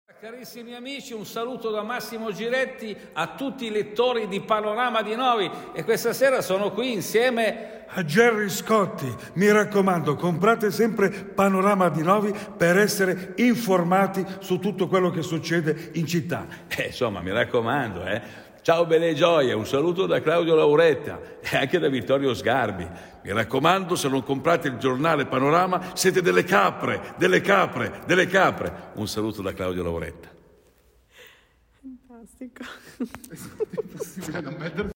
Intanto vi lasciamo con la sua voce.
Vocale-Claudio-Lauretta-per-Panorama-di-Novi.mp3